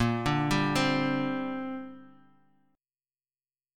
A#6add9 chord